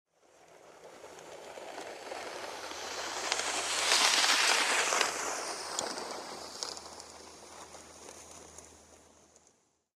Звуки санок
Звук металлических санок на льду